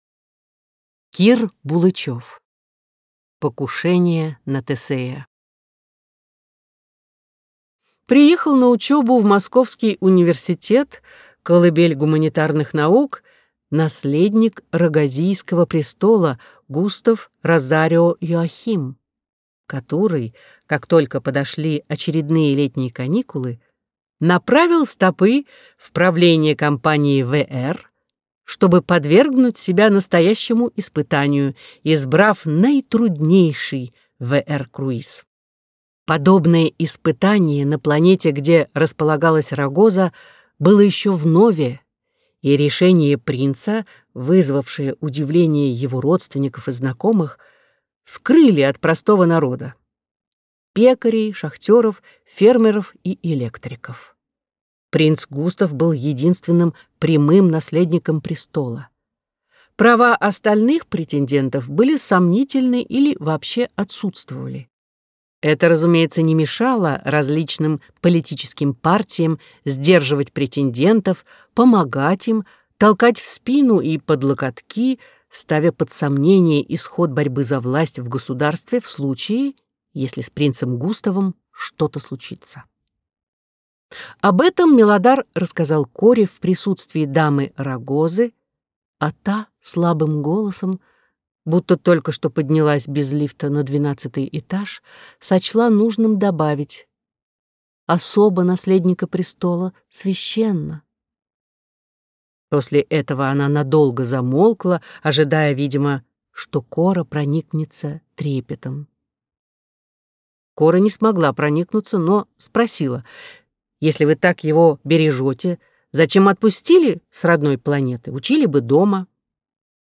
Аудиокнига Покушение на Тесея | Библиотека аудиокниг